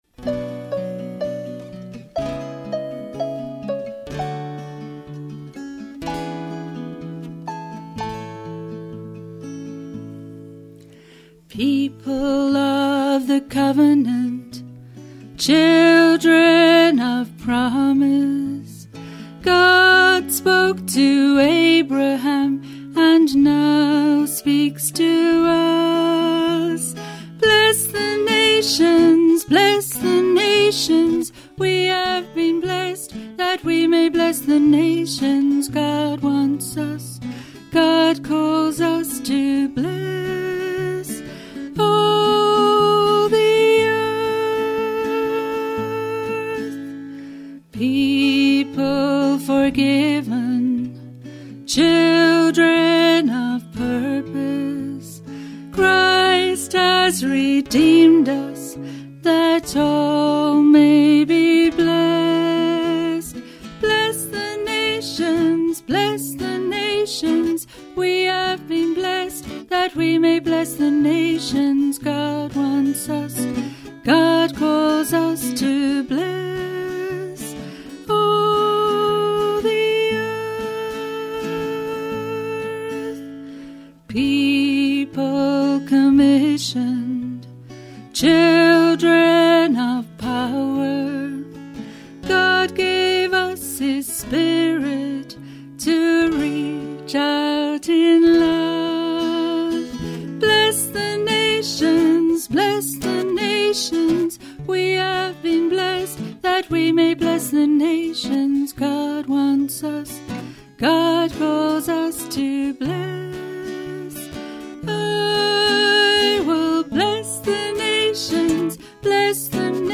guitar vocal